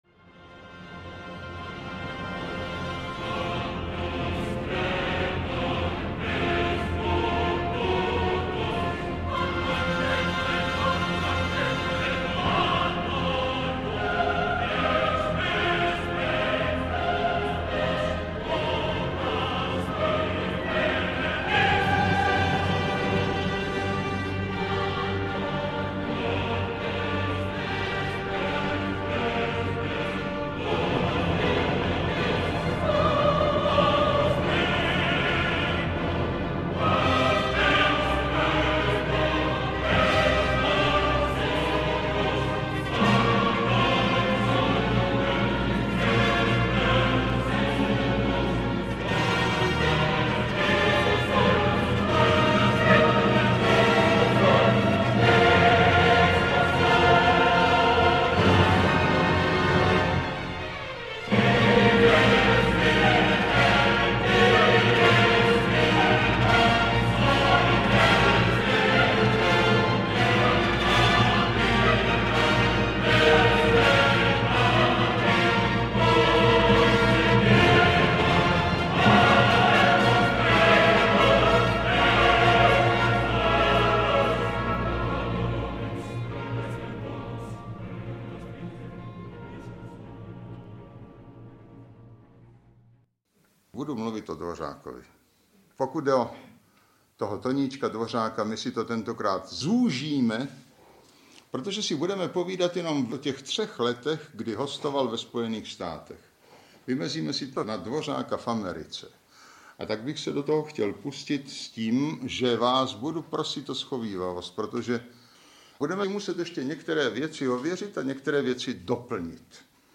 Ukázka z knihy
• InterpretZdeněk Mahler
antonin-dvorak-v-americe-audiokniha